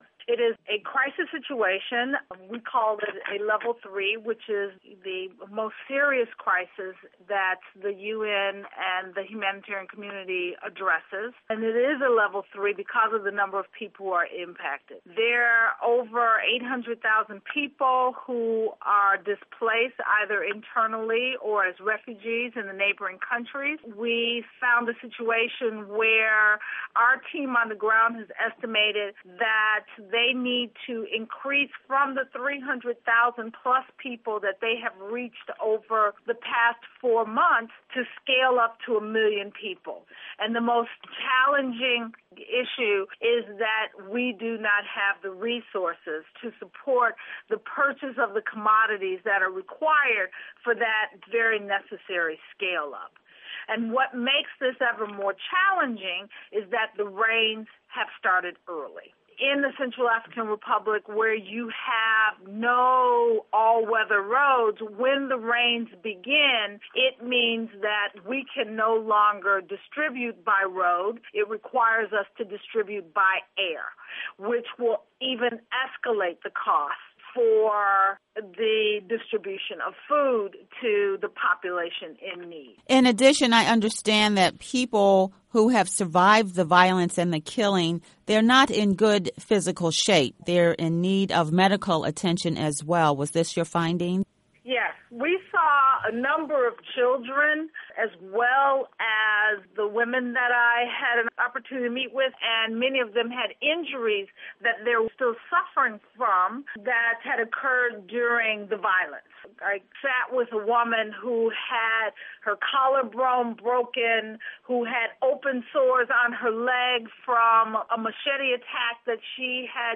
Listen to interview with Ertharin Cousin